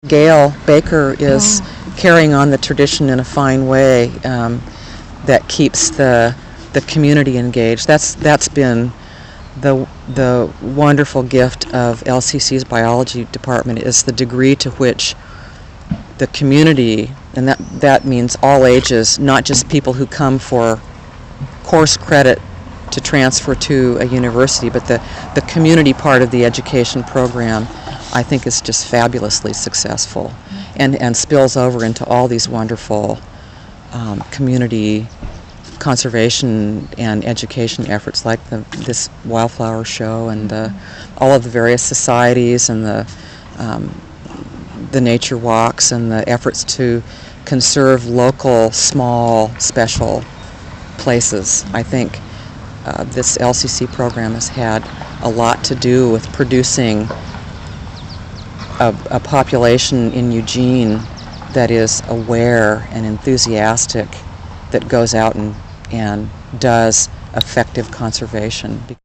Summary of Interview
Location: Mt. Pisgah Arboretum, Eugene, Oregon